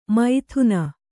♪ maithuna